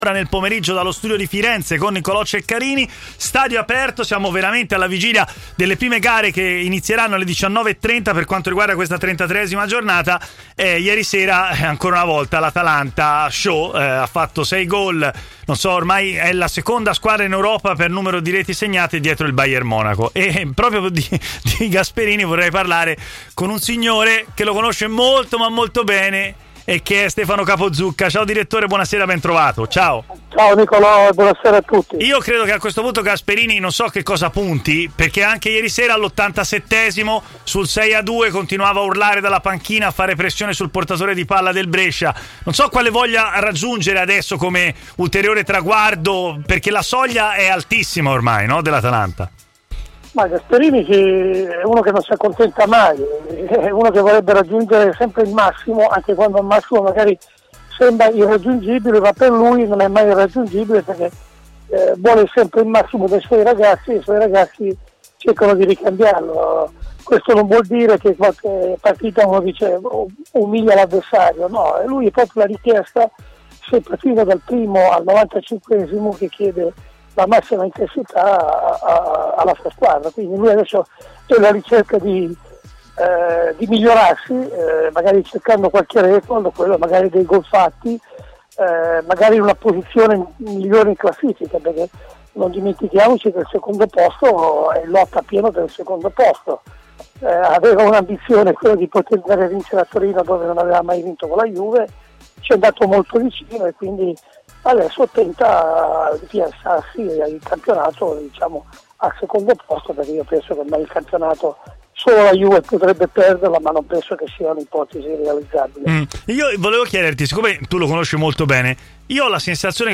è intervenuto in diretta a Stadio Aperto, trasmissione in onda sulle web frequenze di TMW Radio